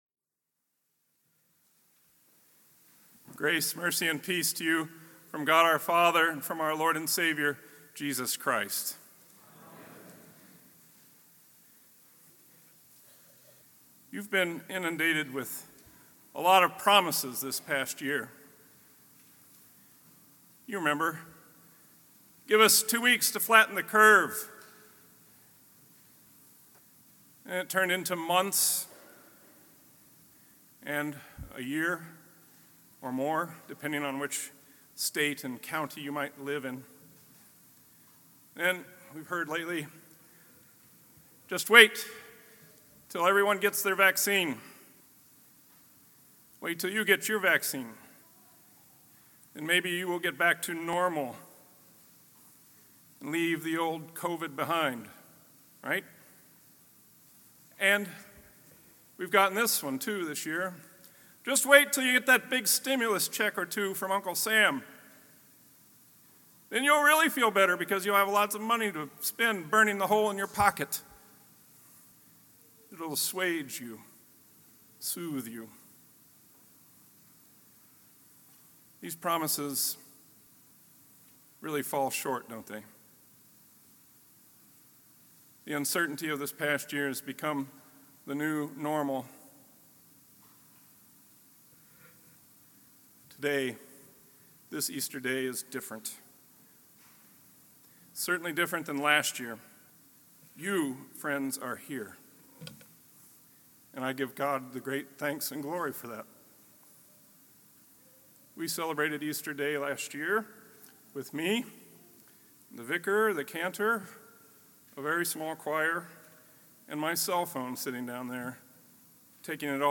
The Resurrection of Our Lord, Festival Divine Service